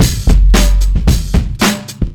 Track 15 - Drum Break 01.wav